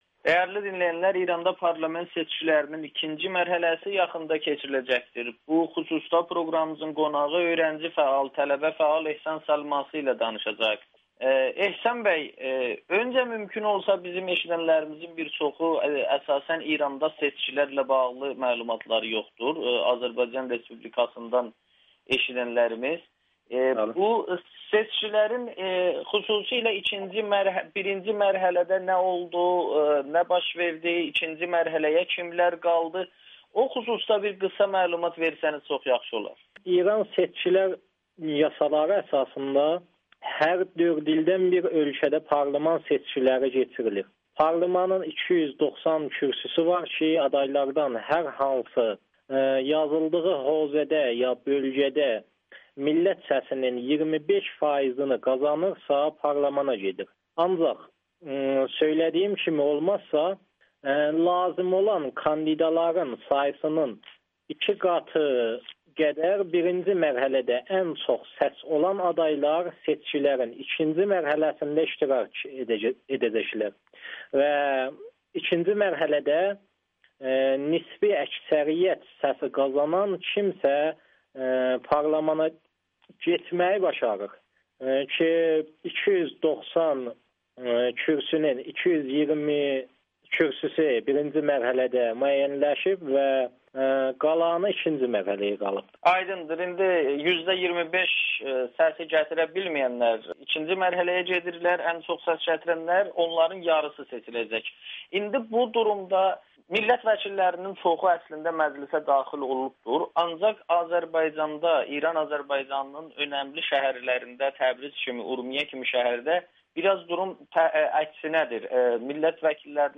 Audio-Müsahibə